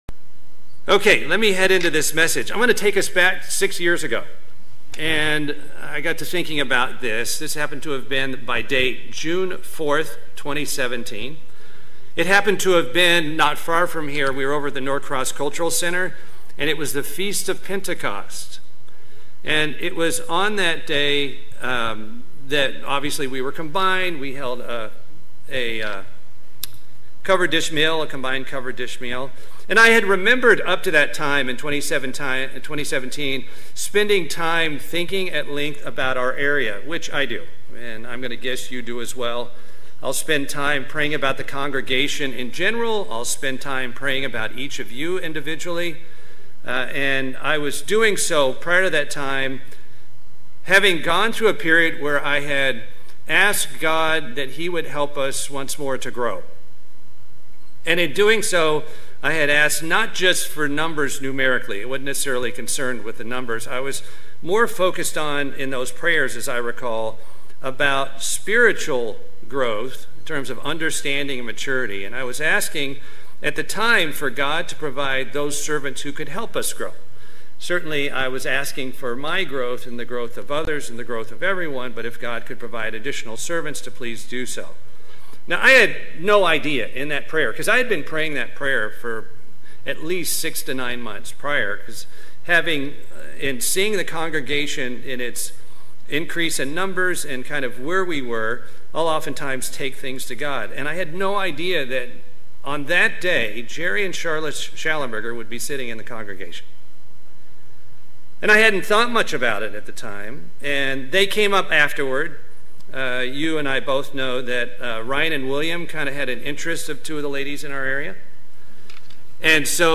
[Note - A power outage affected the hall at about 32:15 into the message.]